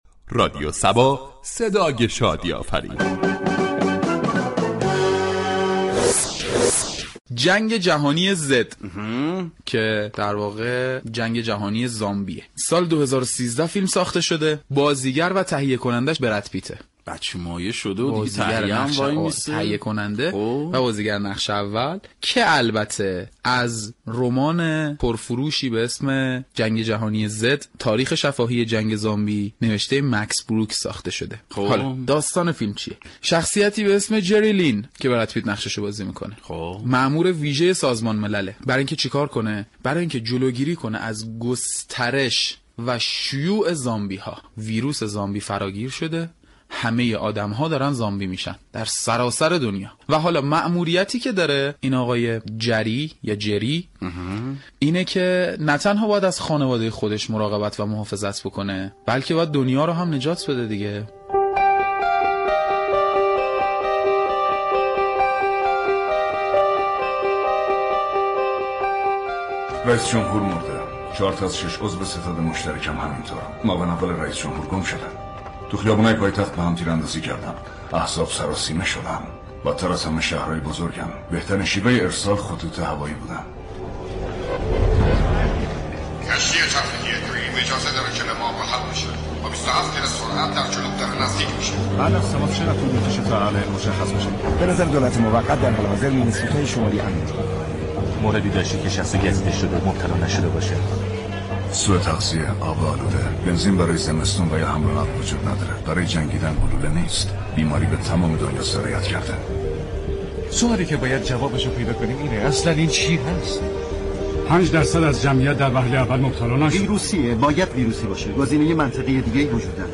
در این برنامه با نقد و بررسی رمان و در كنار آن پخش سكانس های از فیلم مخاطب به خواندن كتاب ترغیب می شود .